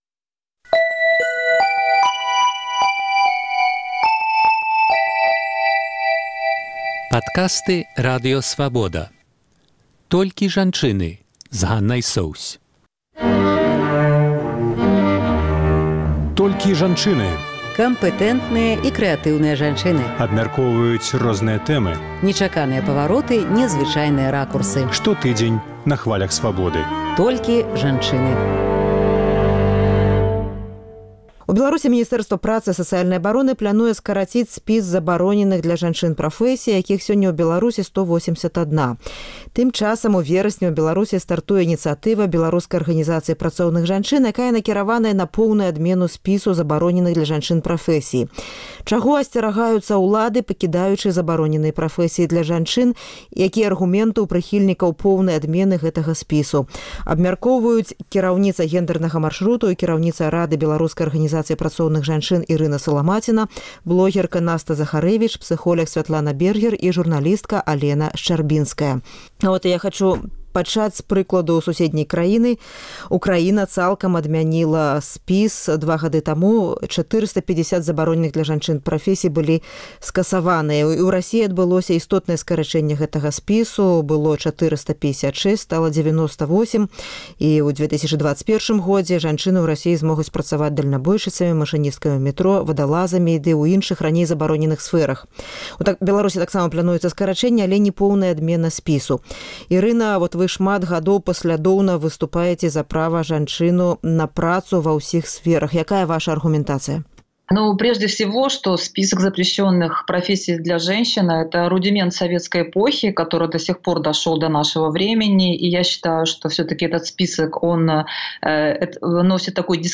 Штотыднёвы круглы стол экспэртаў і аналітыкаў на актуальную тэму.